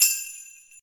soft-hitwhistle.ogg